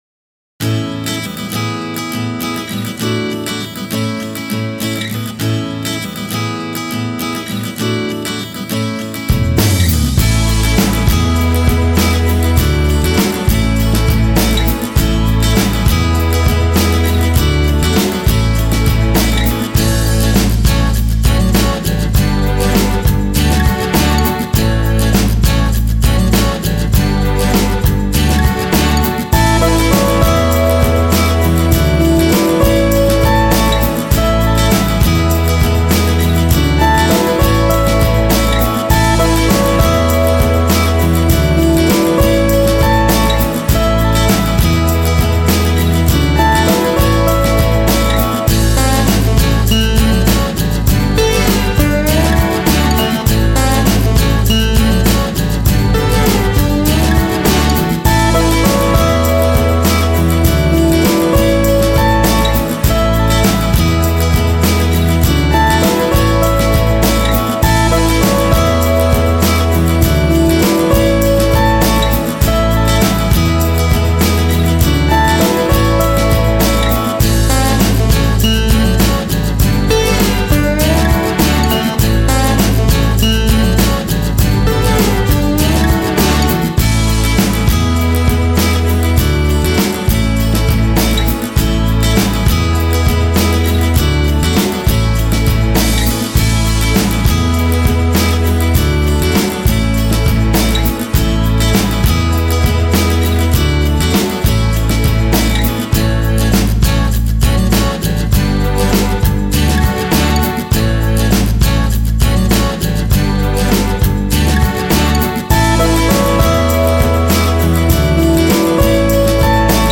это вдохновляющая и жизнеутверждающая композиция в жанре поп